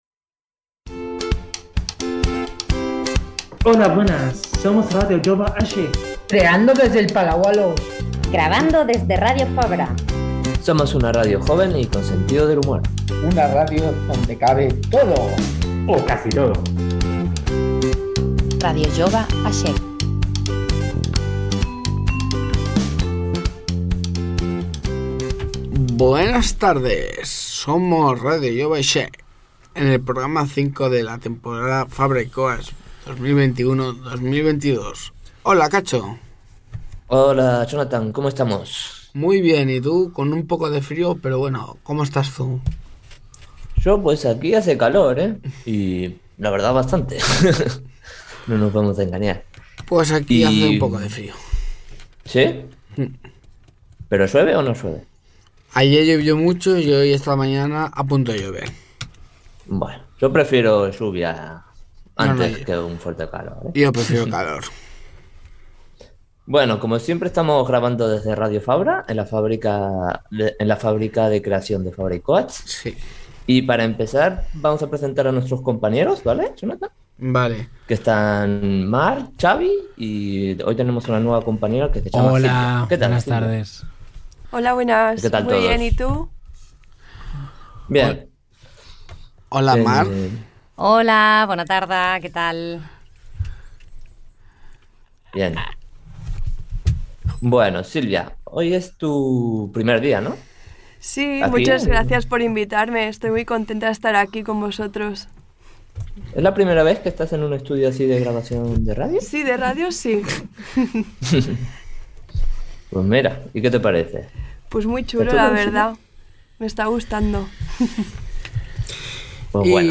Identificació de la ràdio, presentació de l'equip i dels invitats, indicatiu de la ràdio, entrevista sobre un taller de composició de música rap.
Infantil-juvenil
Espai realitzat a Ràdio Fabra, a la Fàbrica de Creació Fabra i Coats de Sant Andreu de Barcelona. Programa fet pels joves de l'Espai Jove del Palau Alós del carrer Sant Pere més Baix de Barcelona.